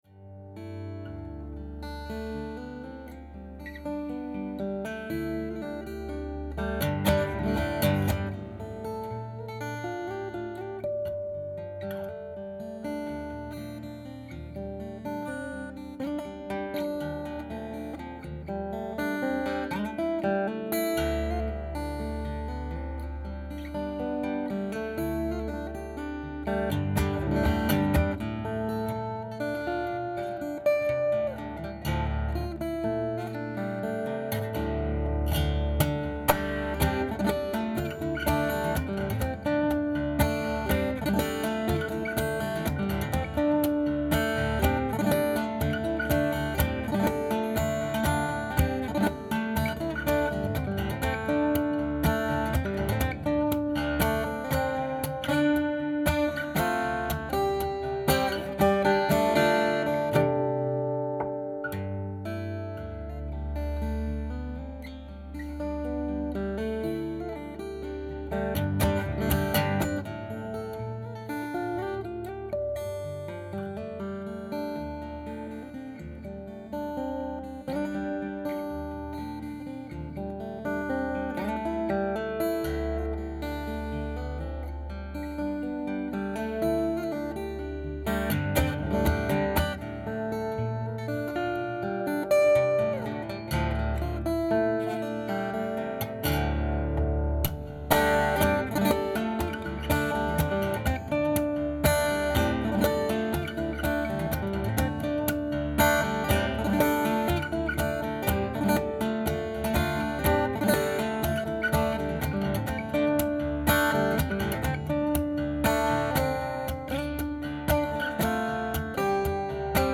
部屋で簡単に録音したものですよ。
この音はスタジオではなく自宅で普通に録音したものです。 つまり，これはライブでそのまま出せるサウンドです。